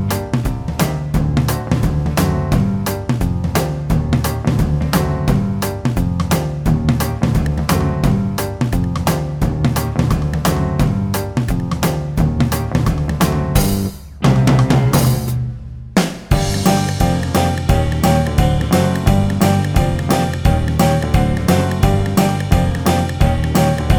no Backing Vocals Rock 'n' Roll 3:31 Buy £1.50